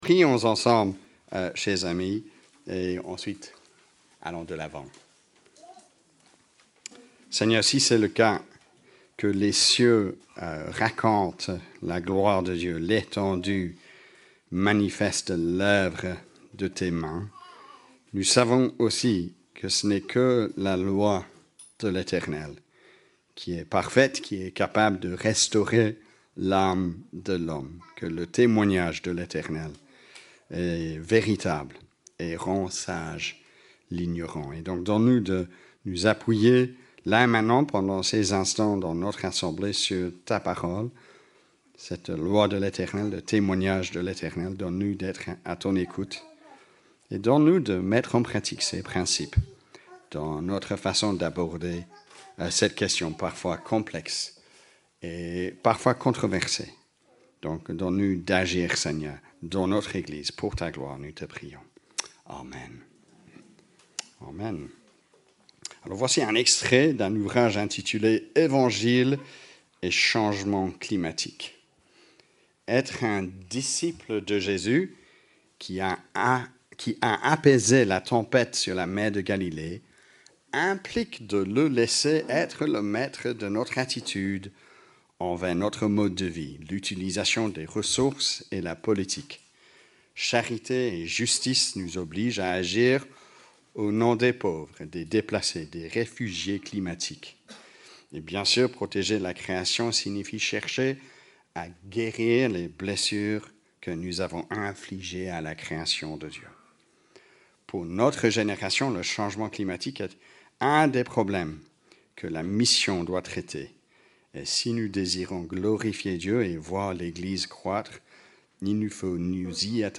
Predication-20-08.mp3